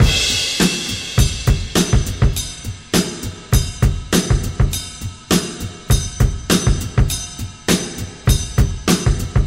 • 101 Bpm Fresh Rock Breakbeat C Key.wav
Free drum beat - kick tuned to the C note. Loudest frequency: 2477Hz
101-bpm-fresh-rock-breakbeat-c-key-sEz.wav